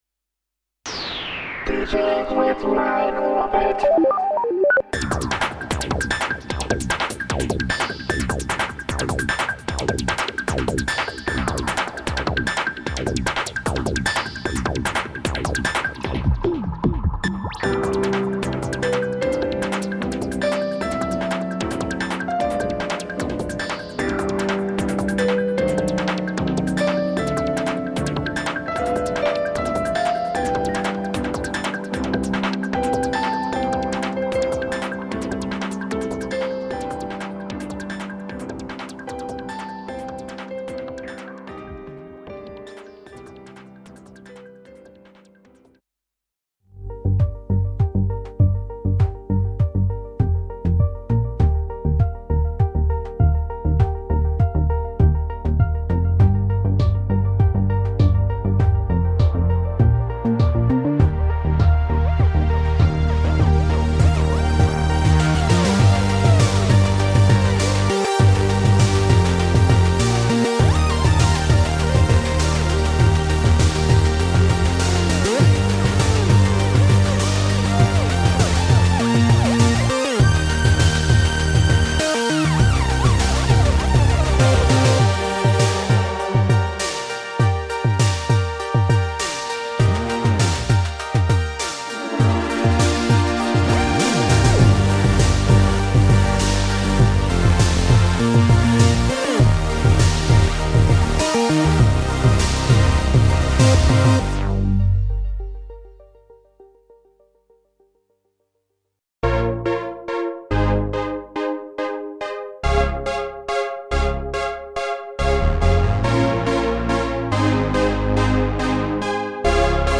Sequential Prophet Rev2 Demo 2017 All sounds recorded directly from the Prophet Rev2 16-voice analog poly synth. No additional processing/EQ/effects or external sequencing. “Prophet Rev2 Theme” is multi-tracked, all other demos recorded in a single pass directly from the Prophet Rev2.
Sequential TAKE 5 Demo 2021 Take 5 audio demos. All sounds come directly from the Take 5, only light limiting added after recording with no additional processing.